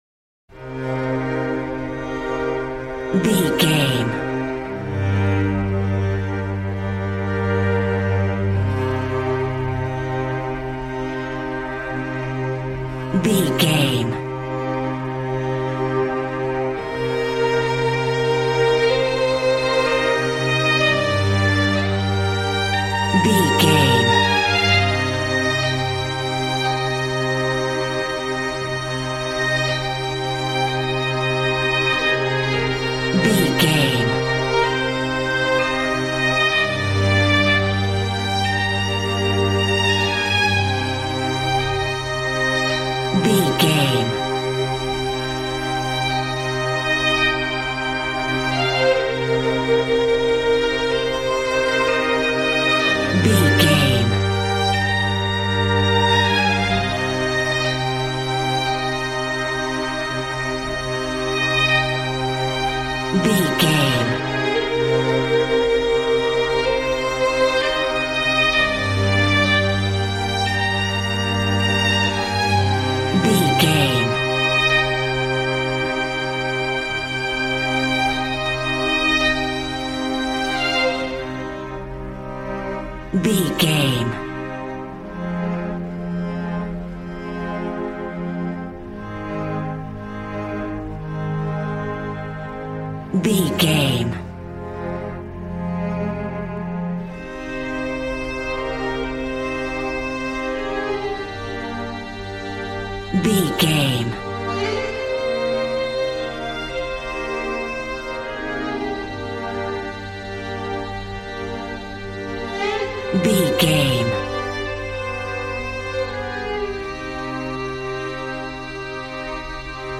Aeolian/Minor
regal
brass